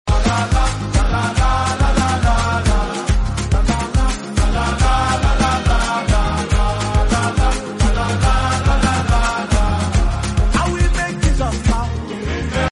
this is just mass choir ooo